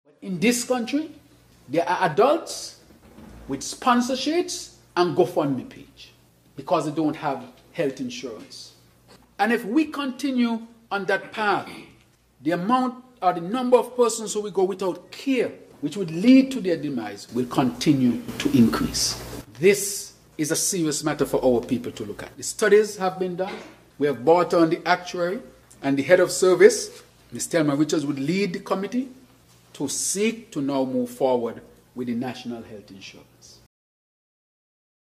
Speaking in Parliament while outlining plans and priorities for 2026, Prime Minister and Federal Minister of Health, the Hon. Dr. Terrance Drew, he emphasized that healthcare should be treated as a right, not a privilege, noting that while children are no longer forced to rely on sponsorship sheets or GoFundMe pages due to the establishment of the Children’s Medical fund the adults face a different reality.